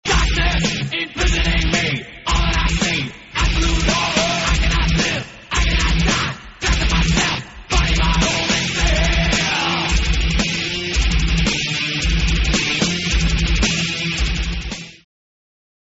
Pop & Rock
Thrash metal, Heavy metal, Hardrock